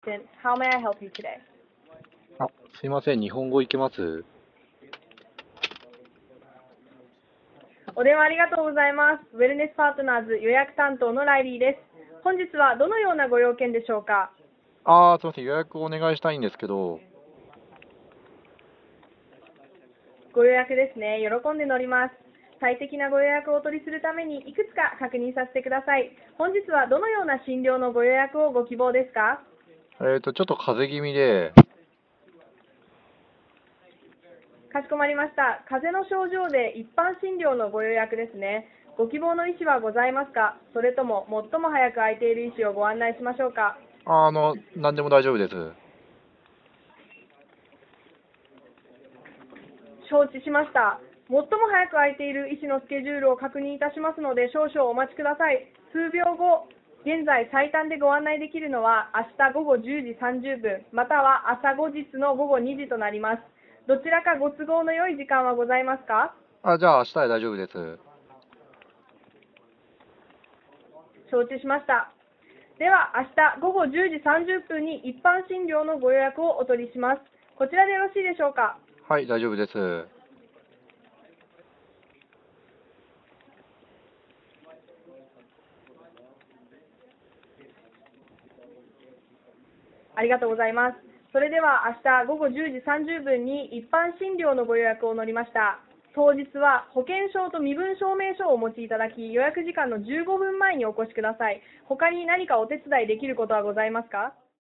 vapi-call-center.mp3